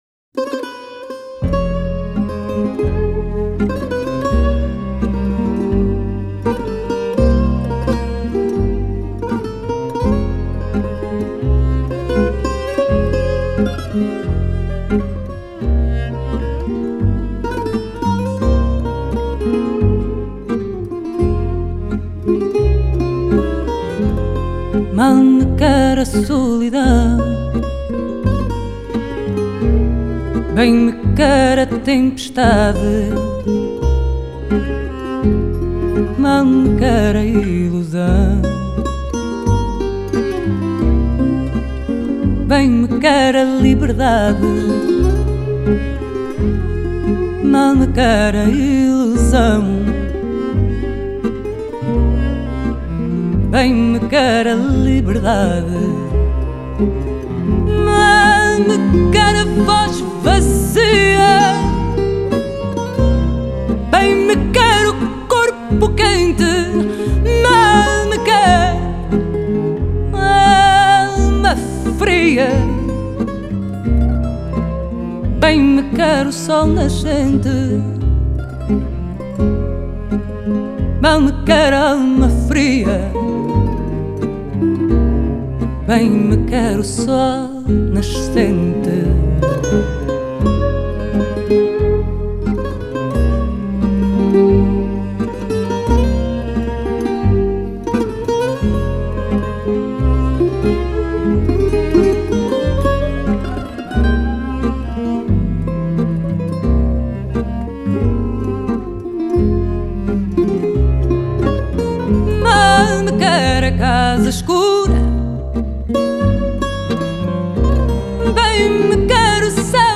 Тема: meu fado